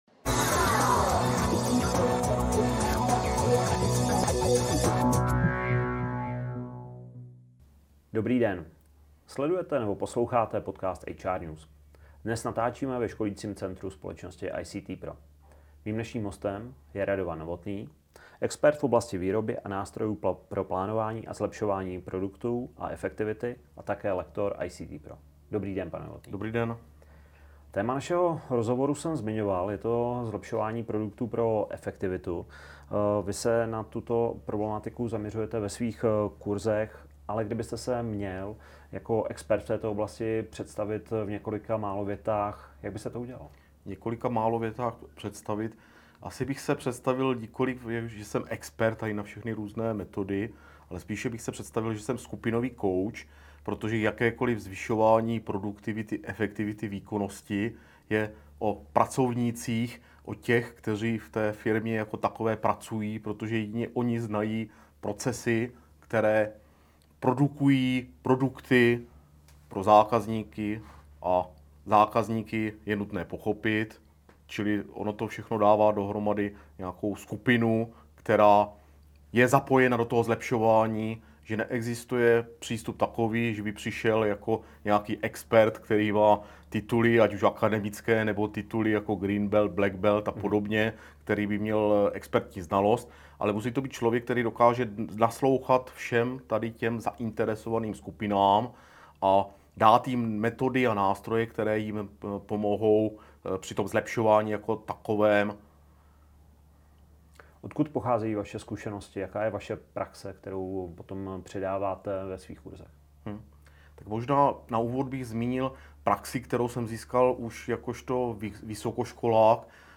Přehrát zvukový záznam videa Co se v rozhovoru dozvíte: Mnoho firem investuje do moderních technologií, ale opravdové zlepšení procesů přináší hlavně změna přístupu k lidem a týmové spolupráce, ne jen nástroje.